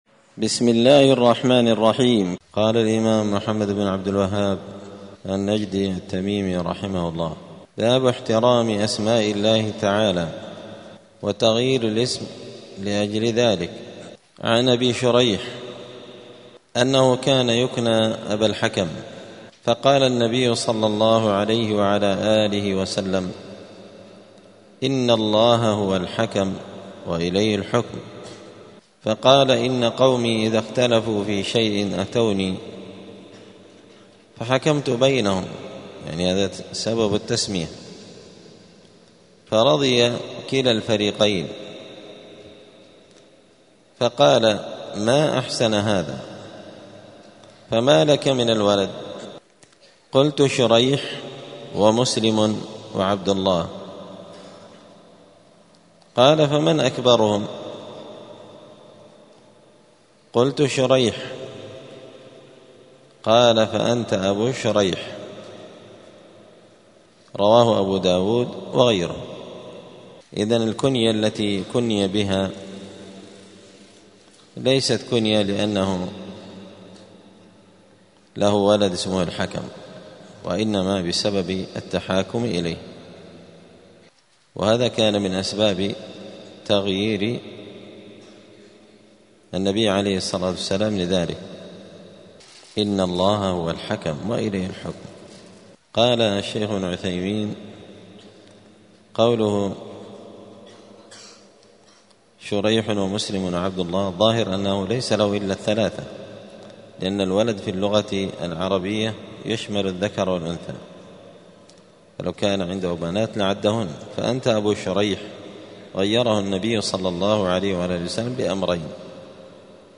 دار الحديث السلفية بمسجد الفرقان قشن المهرة اليمن
*الدرس السادس والعشرون بعد المائة (126) {باب احترام أسماء الله تعالى وتغيير الاسم لأجل ذلك}*